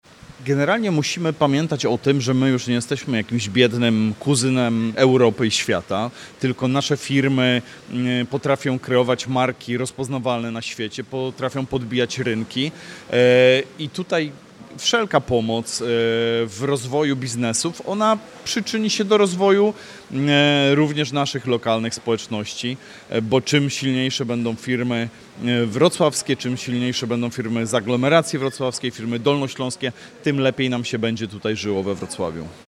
– Przedsiębiorcy będą mogli organizować biznesy, prowadzić ekspansję i korzystać z bezpłatnych usług Polskiej Agencji Inwestycji i Handlu do rozwoju swoich firm – mówi Sergiusz Kmiecik Przewodniczący Rady Miejskiej Wrocławia.